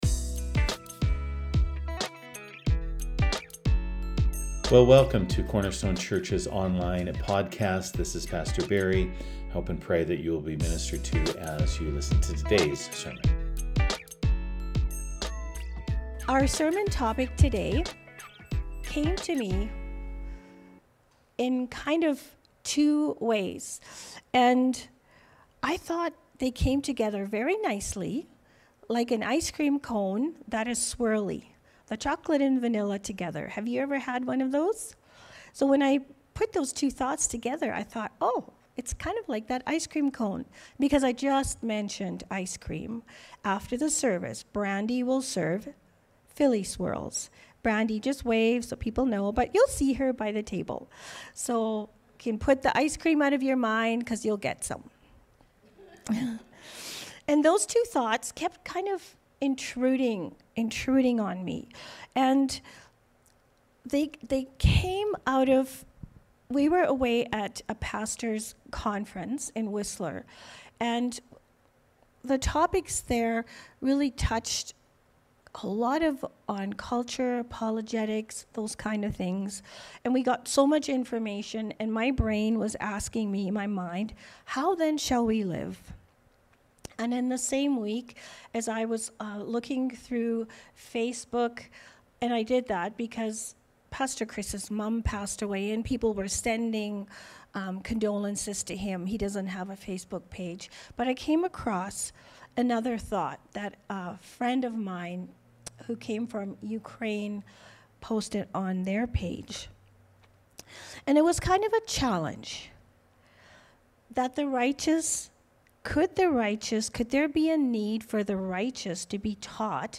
Sermons | Cornerstone Church